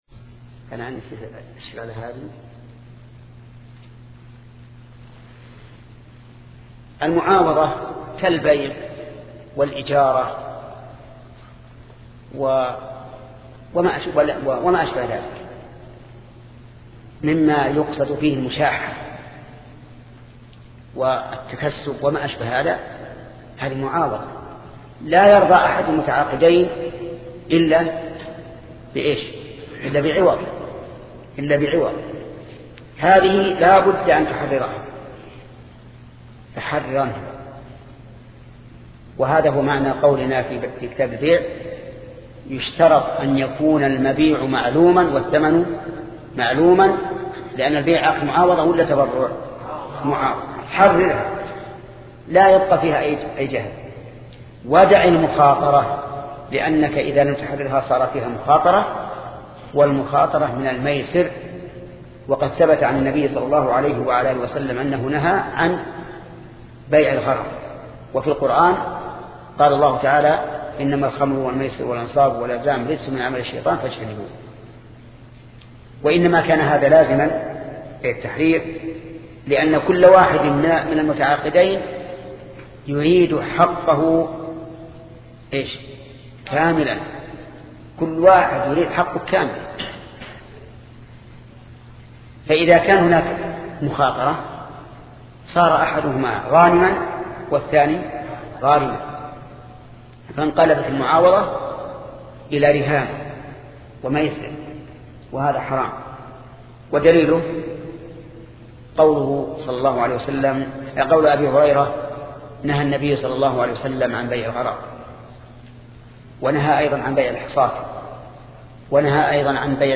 شبكة المعرفة الإسلامية | الدروس | شرح المنظومة في أصول الفقه 10 |محمد بن صالح العثيمين